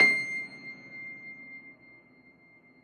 53g-pno22-C5.wav